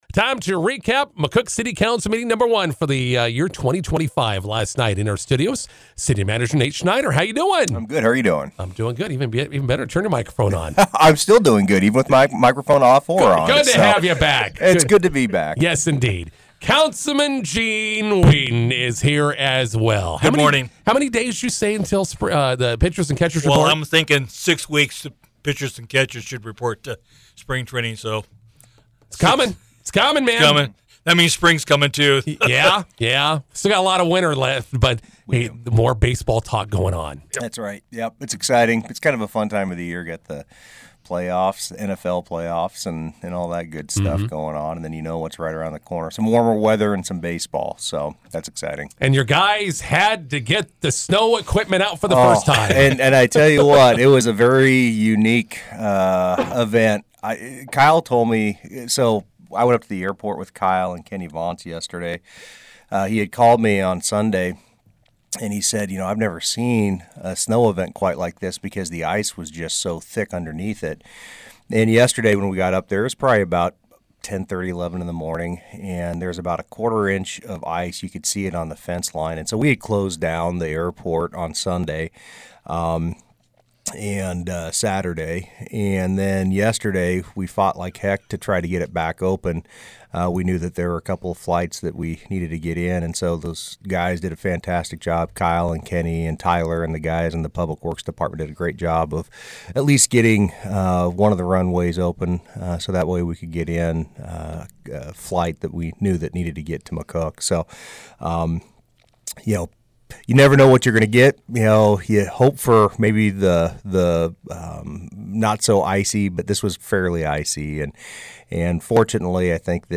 INTERVIEW: McCook City Council meeting recap with City Manager Nate Schneider and Councilman Gene Weedin.